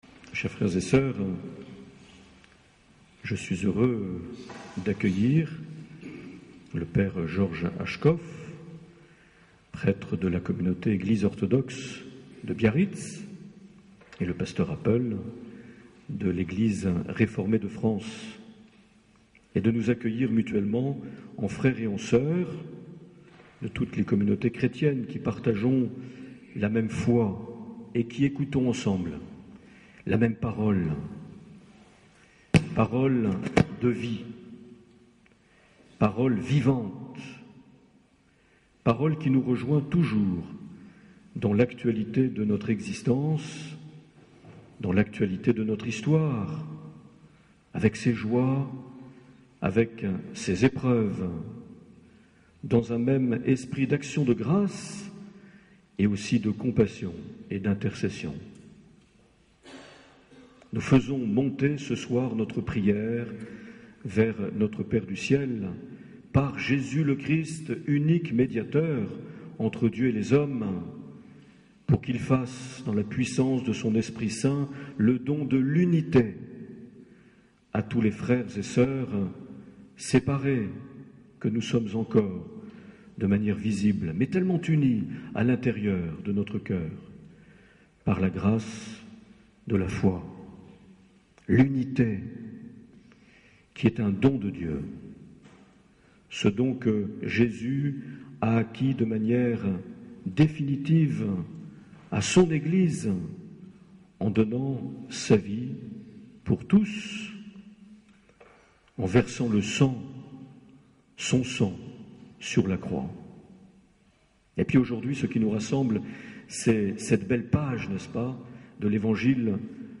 21 janvier 2010 - Carmel de Bayonne - Célébration oecuménique